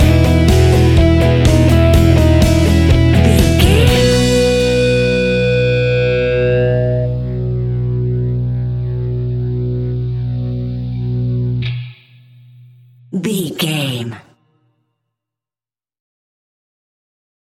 Uplifting
Ionian/Major
fun
energetic
instrumentals
guitars
bass
drums
piano
organ